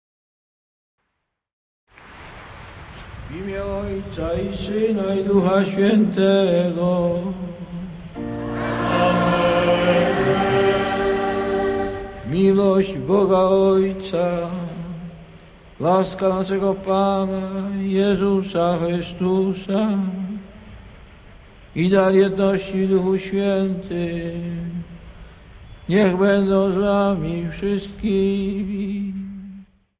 Ojciec Święty w Ojczyźnie - 1997 Wielka Krokiew w Zakopanem
01. WSTĘP - okrzyki, oklaski
„Hej, bystra woda” - gra kapela góralska na przywitanie Ojca Świętego (trad./trad.)